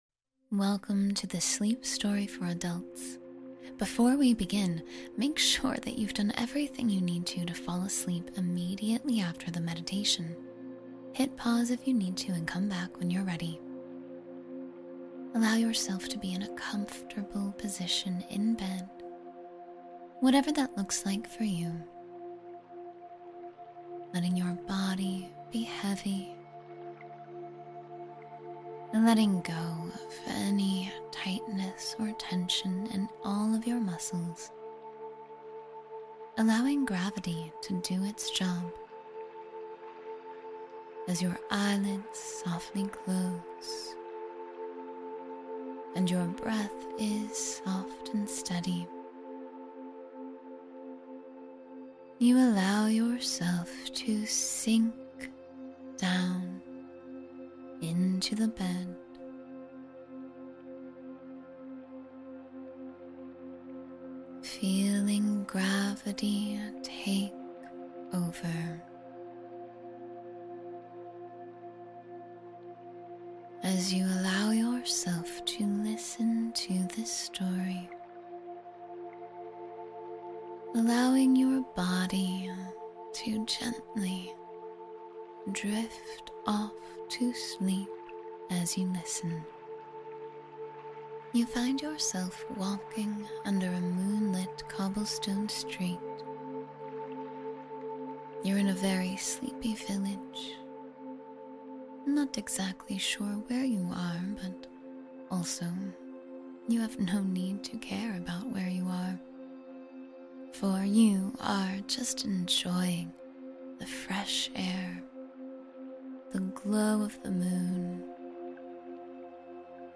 Drift Into Sleep with a Gentle Story — 10-Minute Meditation for Peace